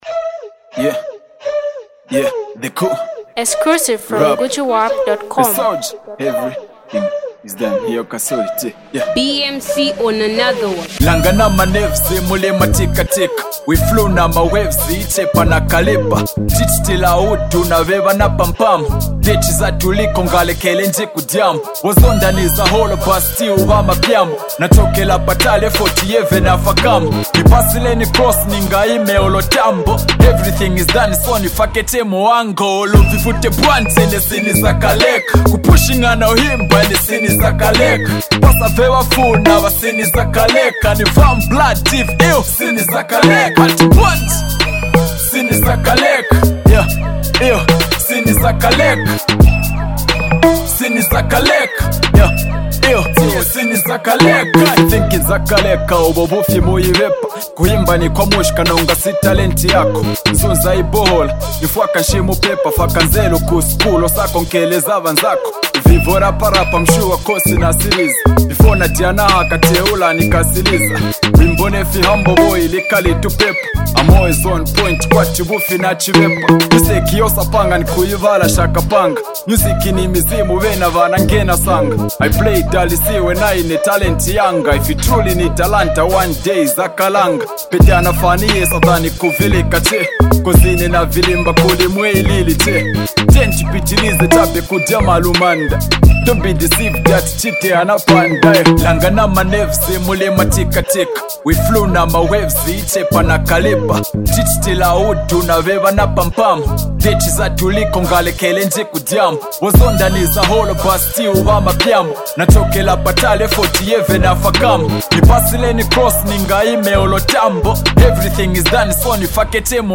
Zambian rap
This inspirational hit
brings a fresh, dynamic sound that resonates with fans.